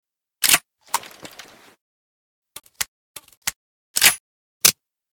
bm16_reload_lr.ogg